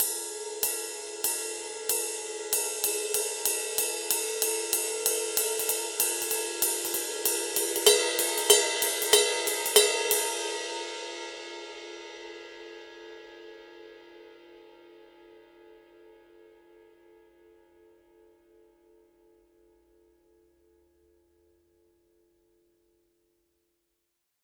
Paiste 20" PST 8 Reflector Medium Ride Cymbal | Nicko's Drum One